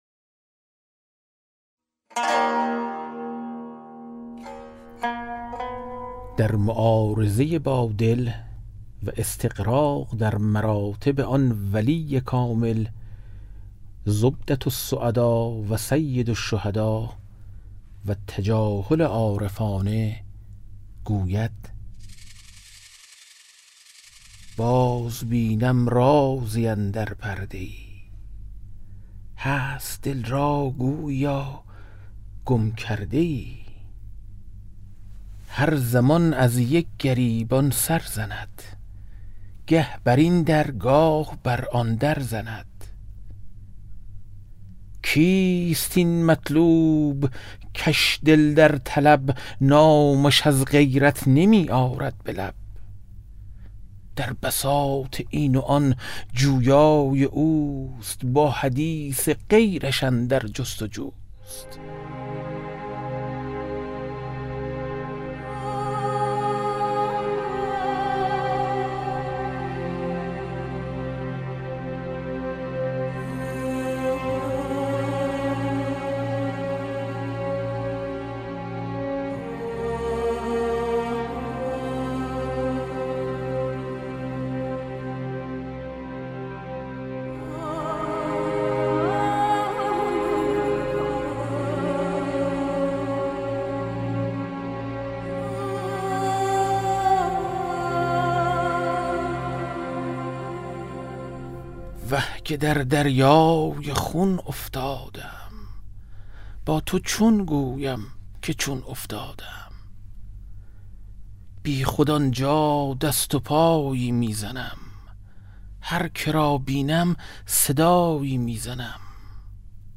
کتاب صوتی گنجینه الاسرار، مثنوی عرفانی و حماسی در روایت حادثه عاشورا است که برای اولین‌بار و به‌صورت کامل در بیش از ۴۰ قطعه در فایلی صوتی در اختیار دوستداران ادبیات عاشورایی قرار گرفته است.